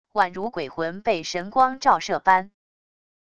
宛如鬼魂被神光照射般wav音频